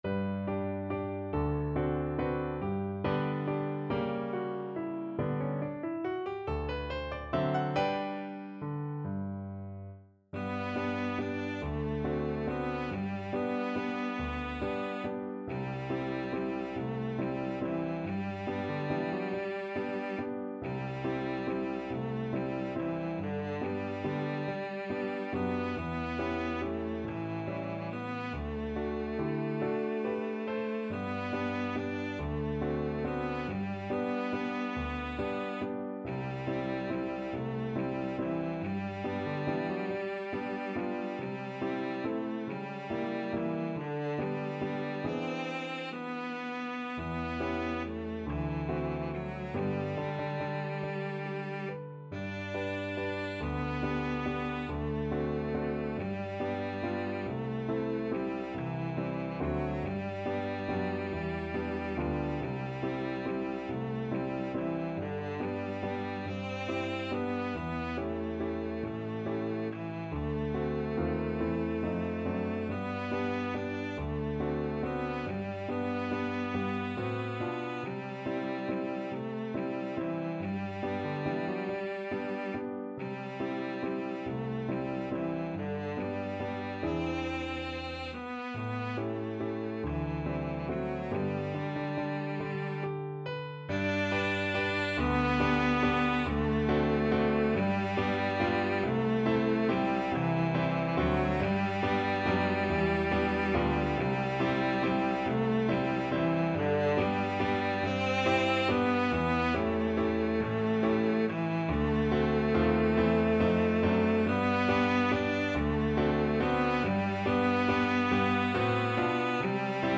D4-D5
3/4 (View more 3/4 Music)
~ = 140 Tempo di Valse